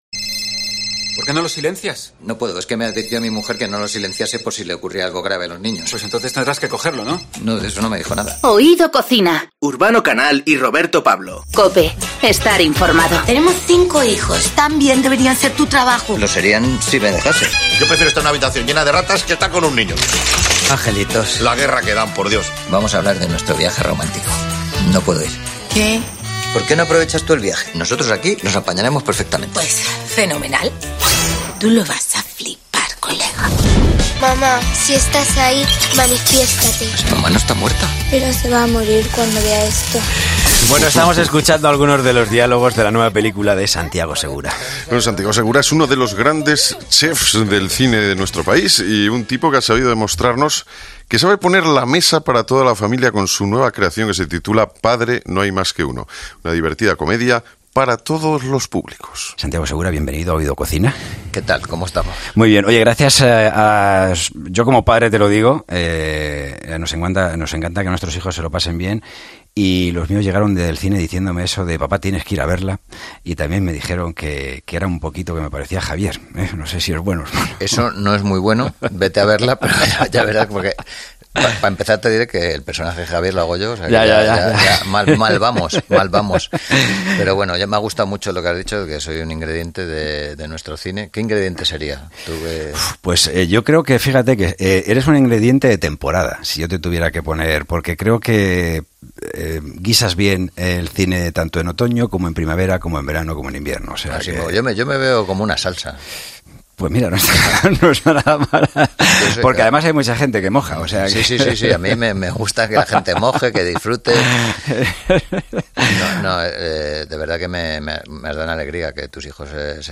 Pues en la charla que hemos mantenido con él, nos ha demostrado que también se maneja entre los fogones como un verdadero cher.